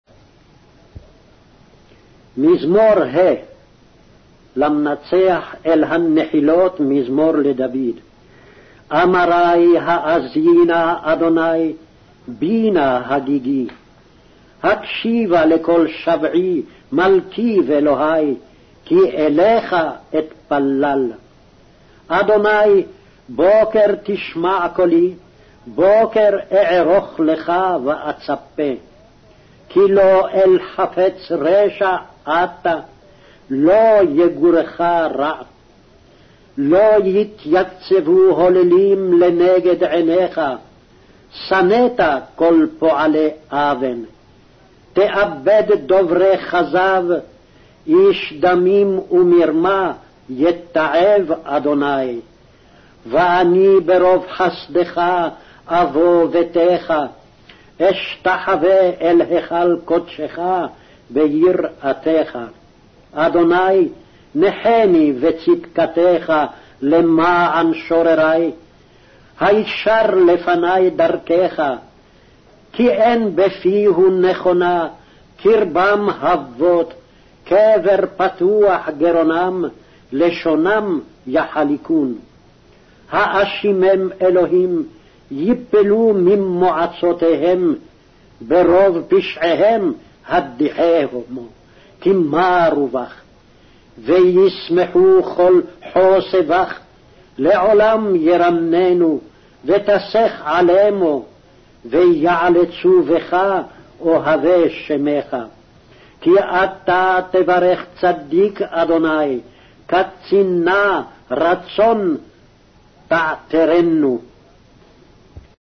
Hebrew Audio Bible - Psalms 25 in Mhb bible version